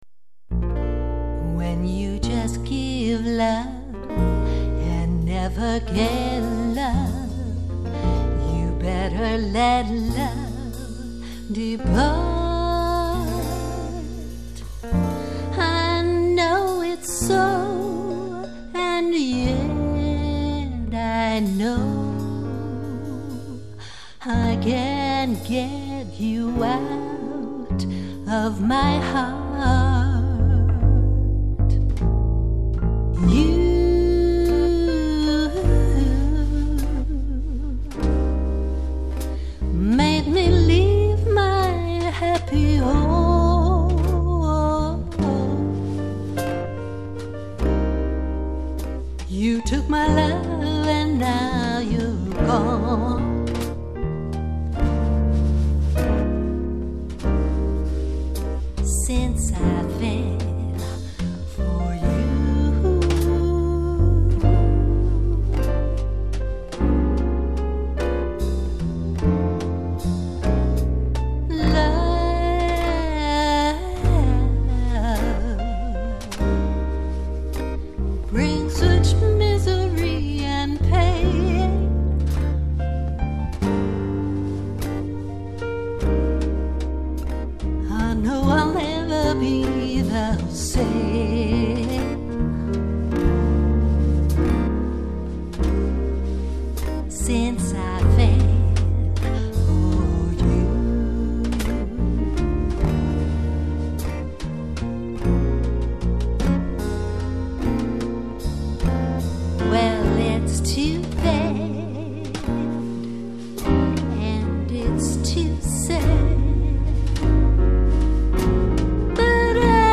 bluesy style and bright but cool singing
vocals and jazz guitar